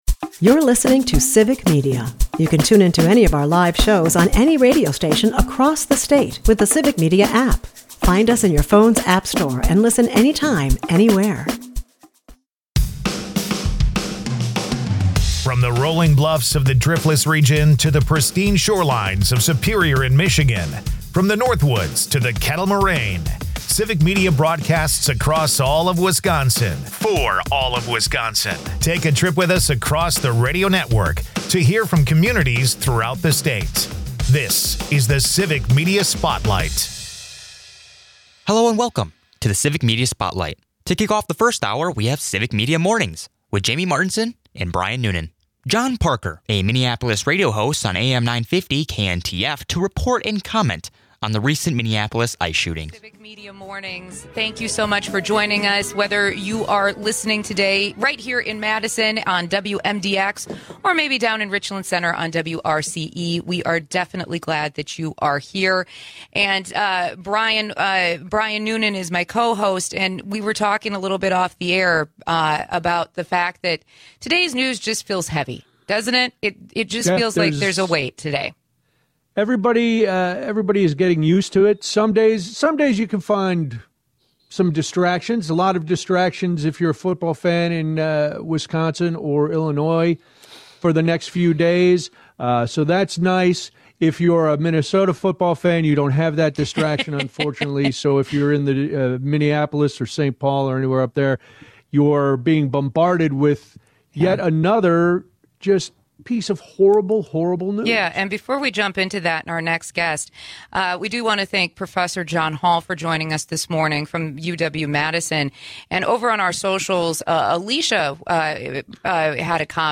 Crowley discusses current county issues and ongoing initiatives impacting Milwaukee residents.